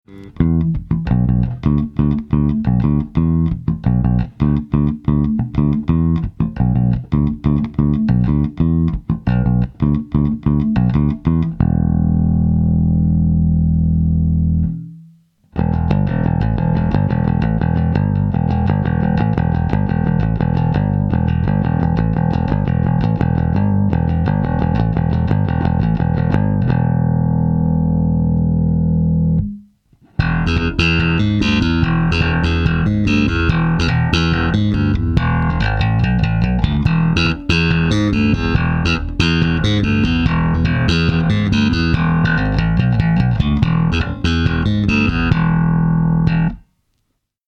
Супер-низких частот от такого маленького динамика (6") вы, естественно, не получите, но звук бас-гитары (и не только) передается отлично.
Примеры звучания комбо через микрофон, в линию и вместе с гитарой прилагаются.
2. Aria AB-20 в линию 1,12 Мб
aria_ab-20_line.mp3